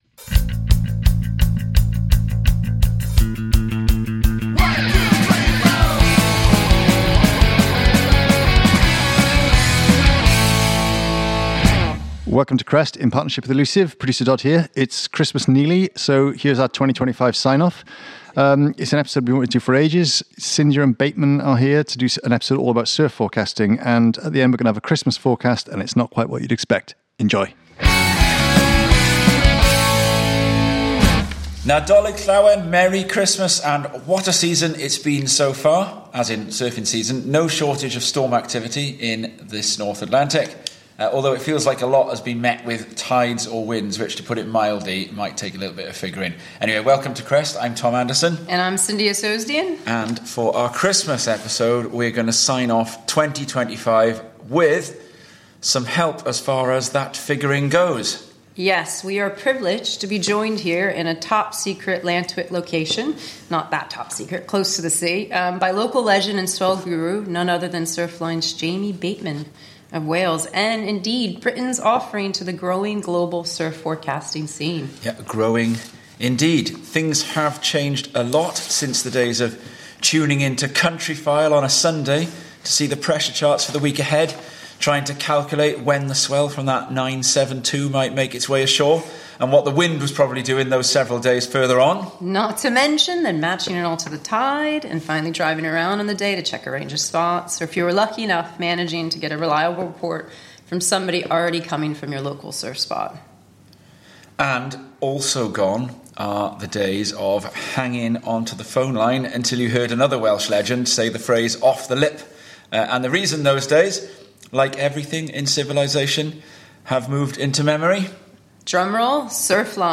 Interviews with local, national and international legends, topical discussion and general stoke!